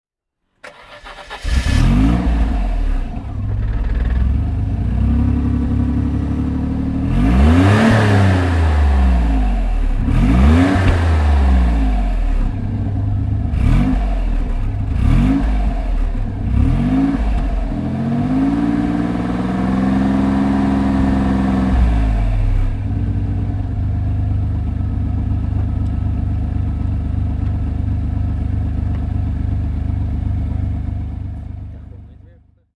Maserati Ghibli 2.8 (1997) - Starten und Leerlauf
Maserati_Ghibli_1997.mp3